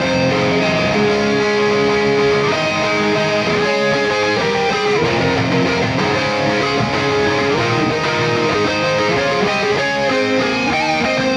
これはアナログコンソールを通った時のノイズを足せるという代物です。
ドライブ感が増してカッコいいギターの音になりました。